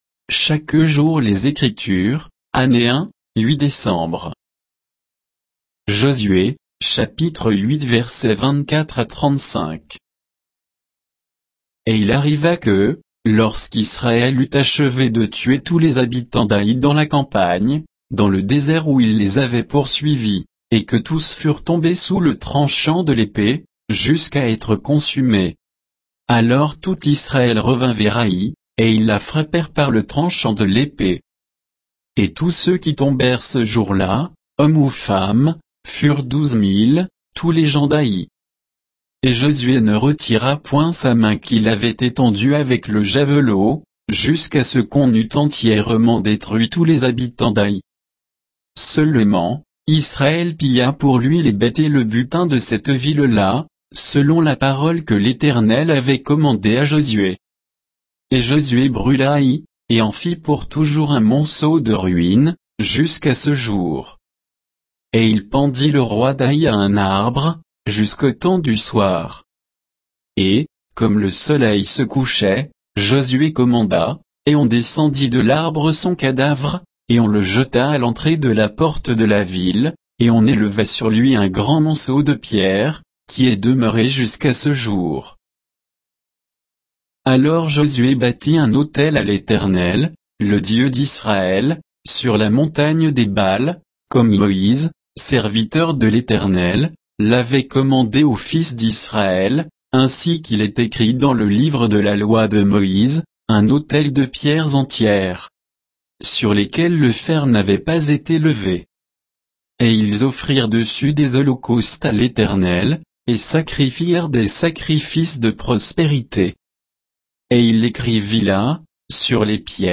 Méditation quoditienne de Chaque jour les Écritures sur Josué 8